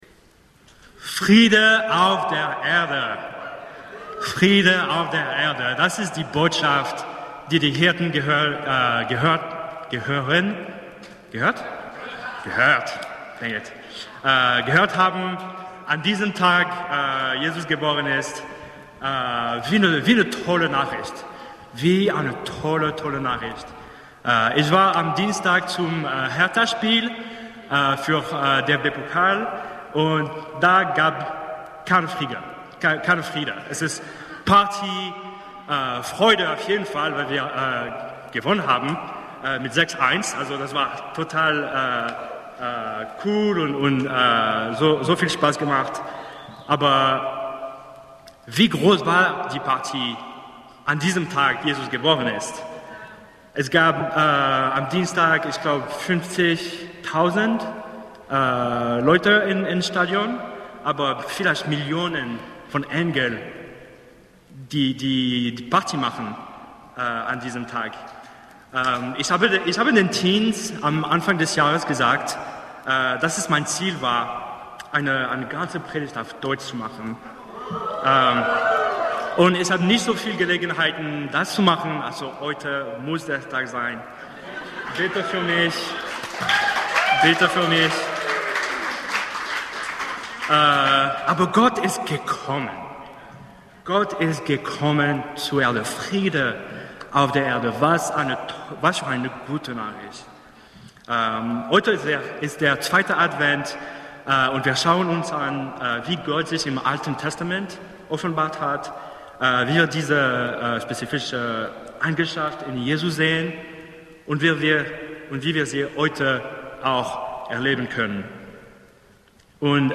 Friede auf Erden ~ BGC Predigten Gottesdienst Podcast